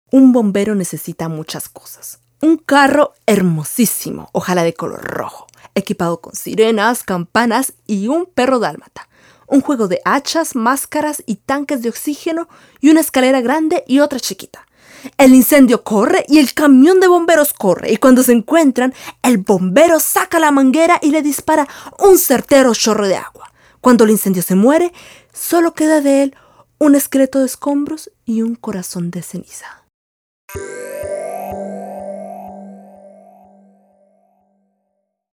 Spanisch Sprecherin
spanisch Südamerika
kolumbianisch
Sprechprobe: Sonstiges (Muttersprache):